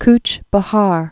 (kch bə-här)